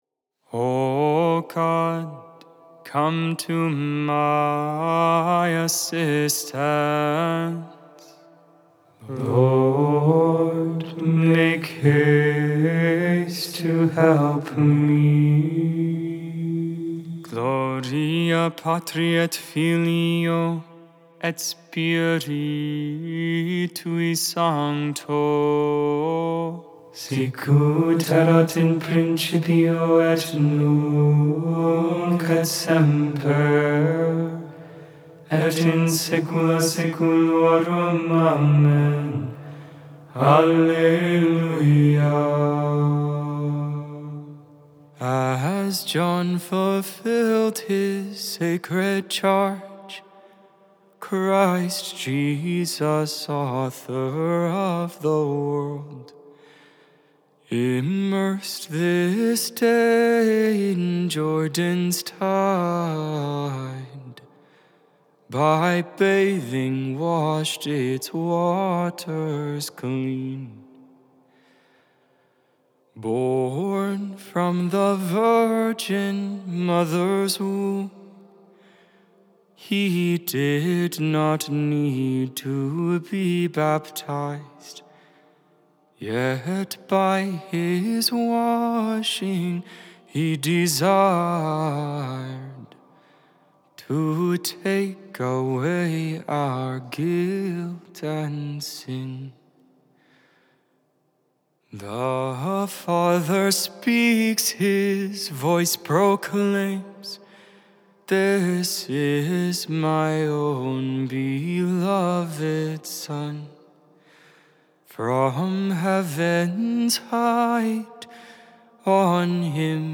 1.12.24 Vespers, Sunday Evening Prayer of the Liturgy of the Hours